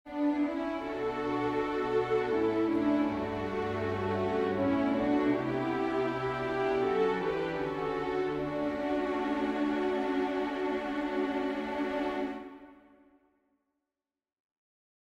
Key written in: D Major
How many parts: 4
Type: Barbershop
All Parts mix: